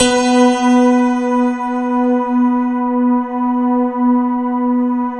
SYN_Piano-Pad2 r.wav